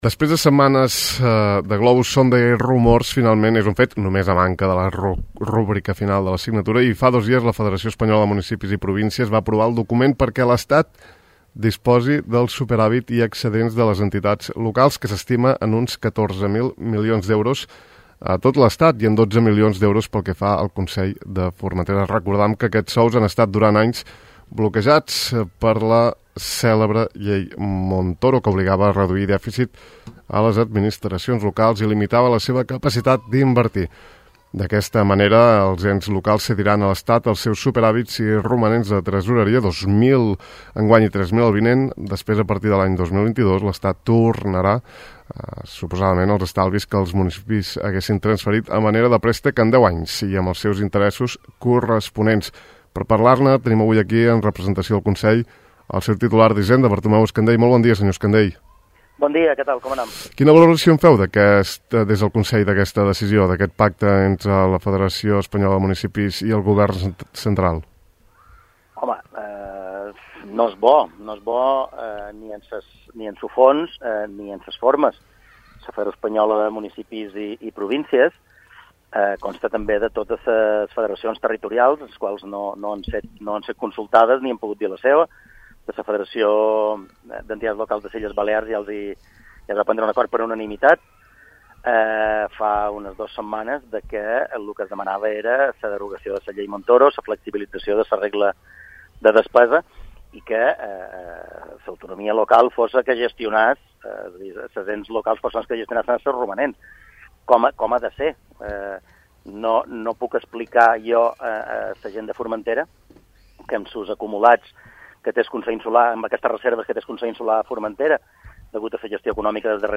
La Federació Espanyola de Municipis i Províncies va aprovar dilluns passat el document perquè l’Estat disposi del superàvit i excedents de les entitats locals, que s’estima en 12 milions d’euros pel que fa al Consell de Formentera. En parlam al De Far a Far amb el titular insular d’Hisenda, Bartomeu Escandell, amb qui analitzam la predisposició del Consell a cedir voluntàriament aquests sous i el futur escenari econòmic que es dibuixarà a Formentera després de la temporada.